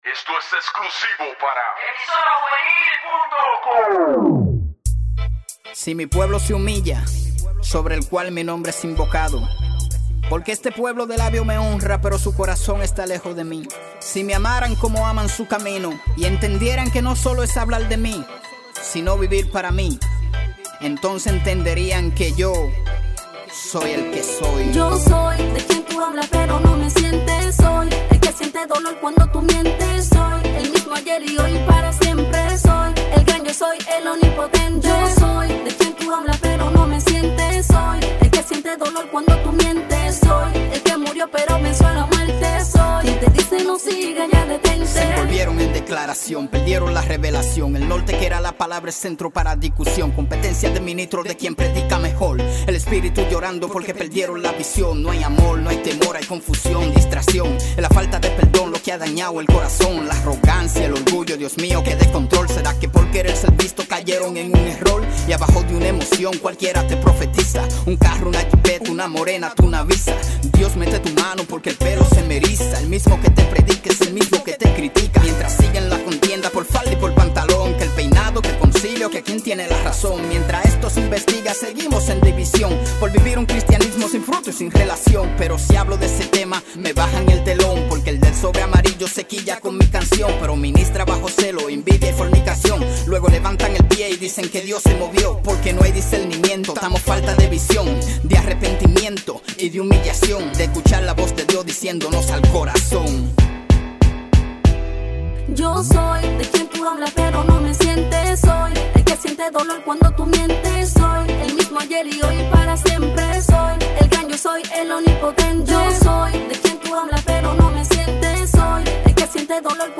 el dúo de música urbana cristiana